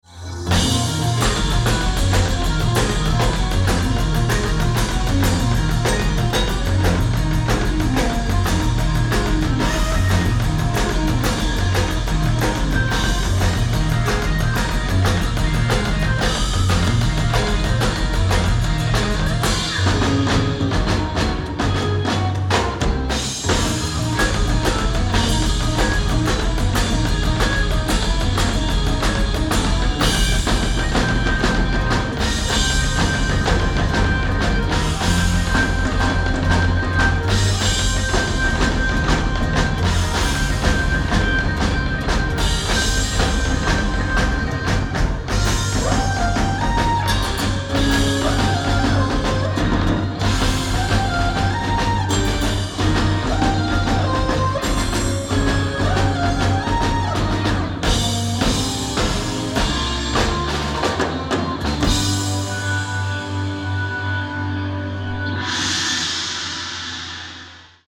Drums, Percussion, Vocals
Guitar, Vocals
Bass, Chapman Stick, Vocals
The concert was broken into two sets.